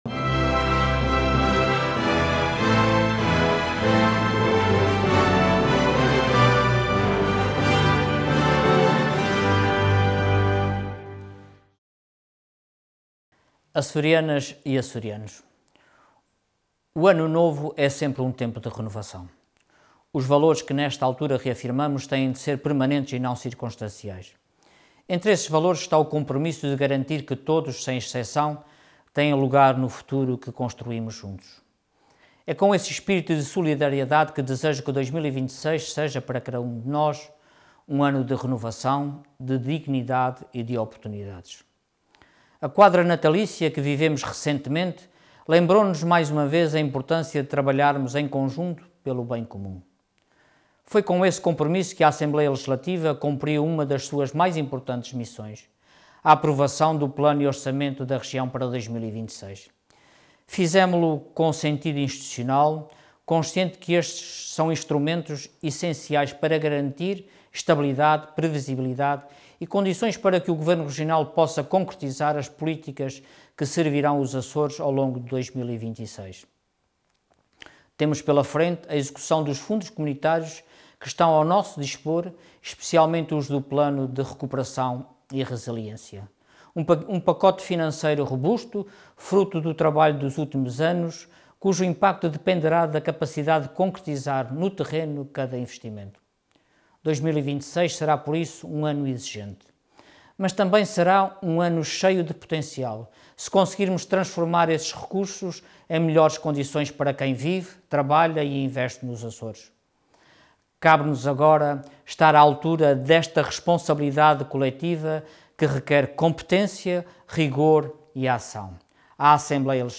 Parlamento online - Mensagem de Ano Novo de S. Exa. o Presidente da ALRAA
Detalhe de vídeo 31 de dezembro de 2025 Download áudio Download vídeo XIII Legislatura Mensagem de Ano Novo de S. Exa. o Presidente da ALRAA Intervenção Orador Luís Garcia Cargo Presidente da Assembleia Regional Entidade ALRAA (Vídeo RTP/A)